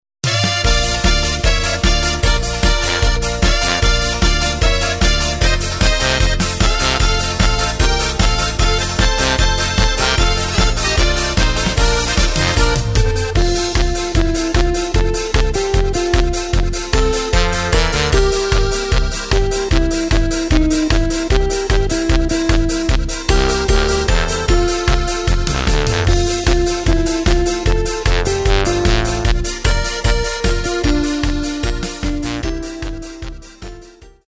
Takt:          2/4
Tempo:         151.00
Tonart:            Bb
Flotte Polka aus dem Jahr 2013!